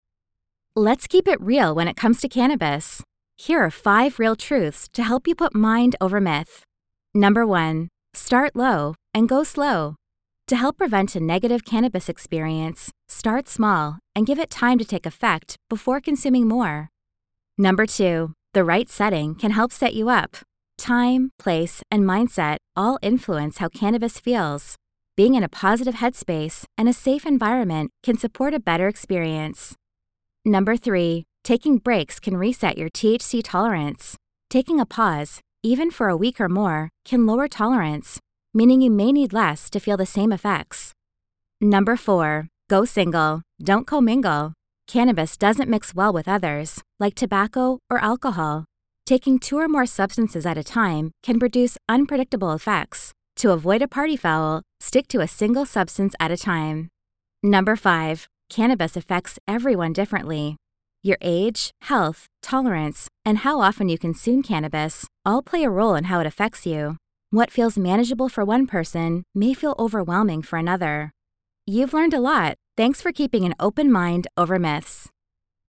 Female
American English (Native) , Canadian English (Native)
Authoritative, Bright, Bubbly, Character, Cool, Corporate, Engaging, Friendly, Natural, Reassuring, Soft, Warm, Young, Approachable, Assured, Cheeky, Confident, Conversational, Energetic, Funny, Sarcastic, Smooth, Upbeat, Versatile, Witty
I’m a Canadian voiceover artist with a native North American accent and a warm, youthful, relatable sound.
I record from a professional home studio with broadcast-quality audio and offer live directed sessions via Zoom, Source-Connect, Microsoft Teams, Google Meet, or phone patch.
Microphone: Rode NT1-A